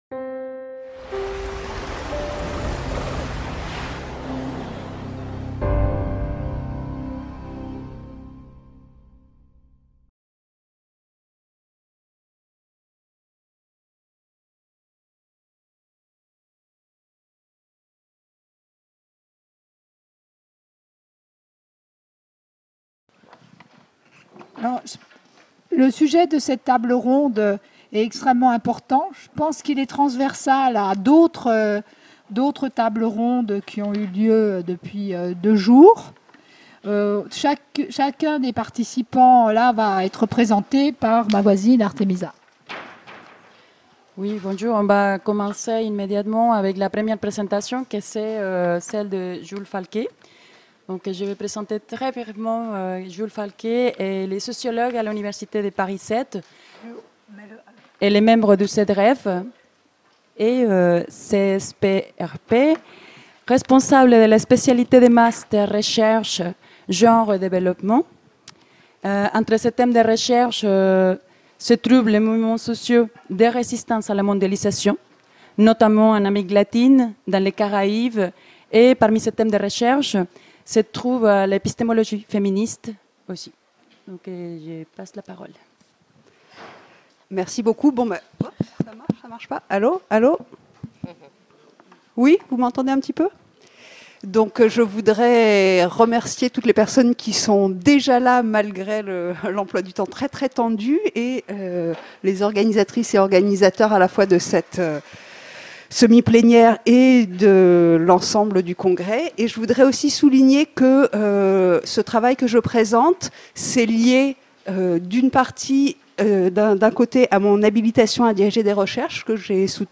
semi-plénière